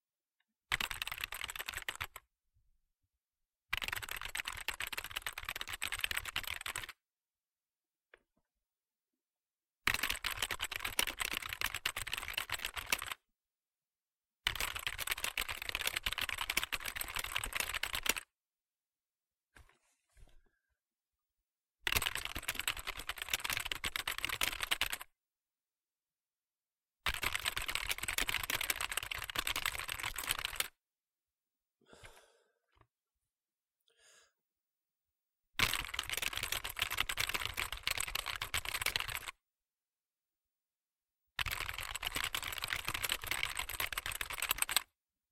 打字
描述：使用微软的键盘打字。大量使用左边的字母，但也使用空格键、回车键，有时使用数字键盘。有一个真正的低音量的环境音。
Tag: 打字 办公 电脑 键盘